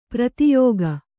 ♪ prati yōga